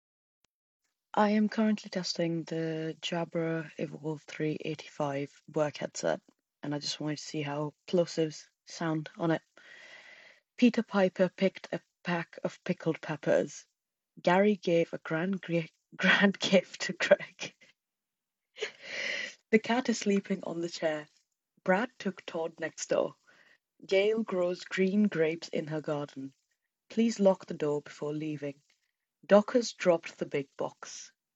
I then recorded myself speaking a few sentences with popping sounds, such as words starting with ‘P,’ ‘B,’ ‘T,’ ‘D,’ ‘K’ and ‘G,’ known as plosives. As you can hear in the clip above (and laugh with me at my goof-up while saying one of the tongue twisters), the ‘P’ sounds don’t sound overly harsh or unpleasant.
Jabra Evolve3 85 — Plosives.mp3